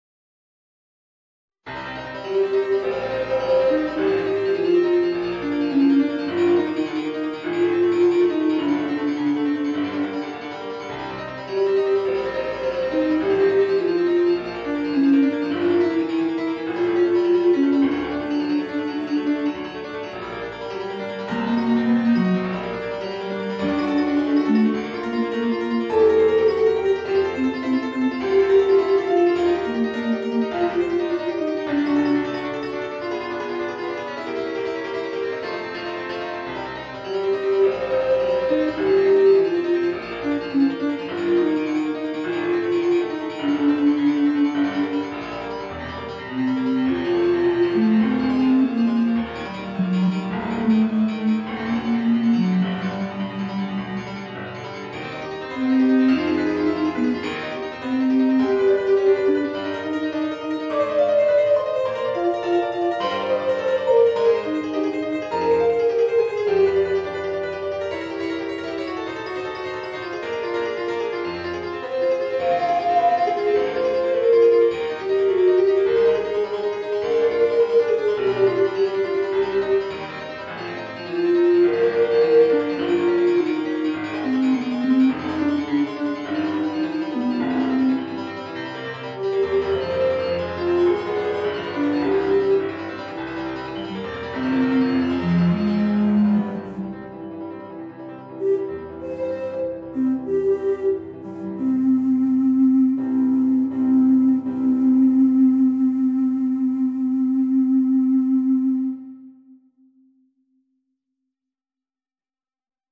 Voicing: Bass Recorder and Piano